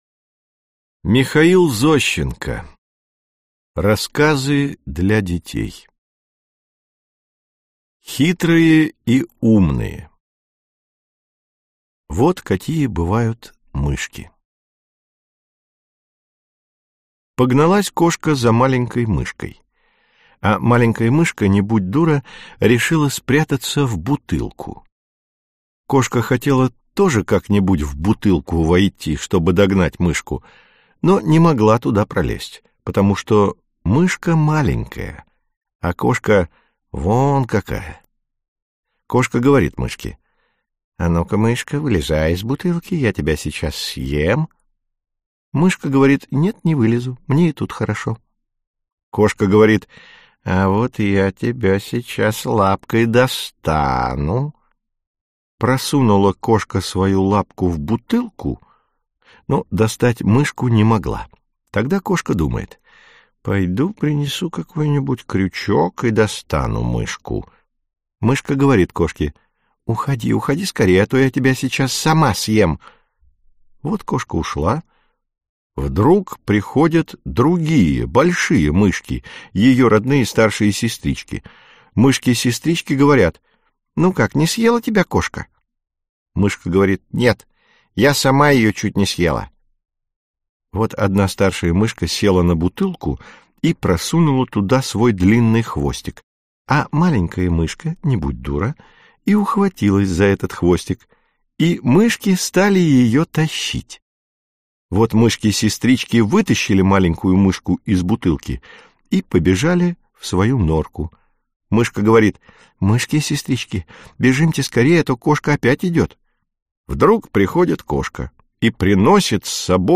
Аудиокнига Рассказы для детей | Библиотека аудиокниг